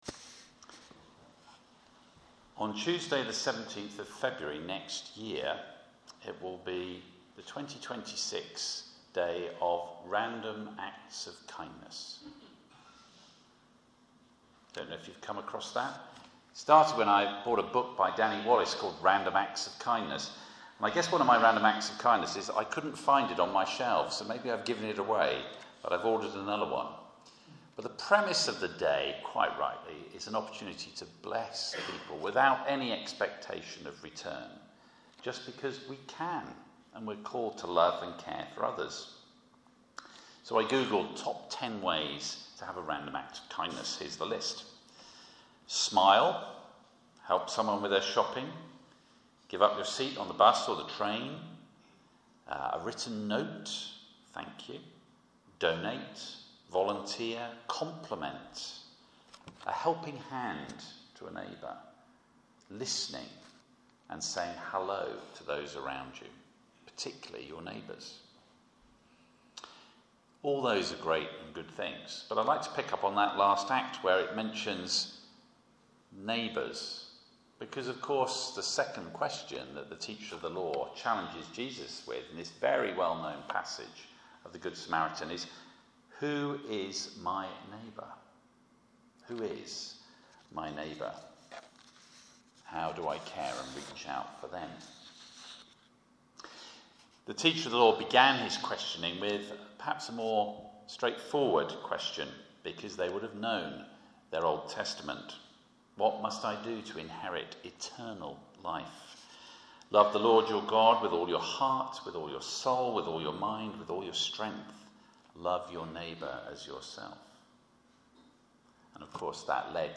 From the Pulpit – The Good Samaritan